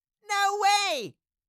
Cartoon Little Child, Voice, No Way Sound Effect Download | Gfx Sounds
Cartoon-little-child-voice-no-way.mp3